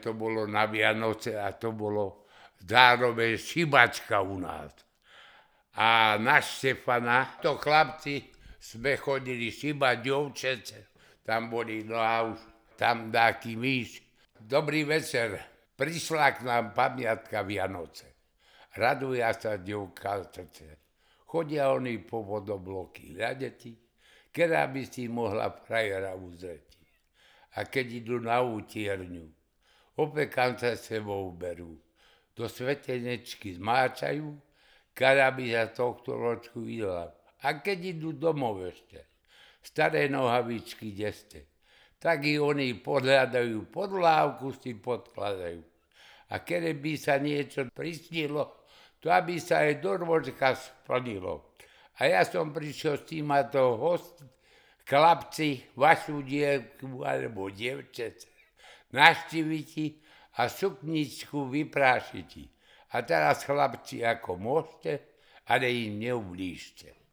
Miesto záznamu Očová
Kľúčové slová koledovanie
vinš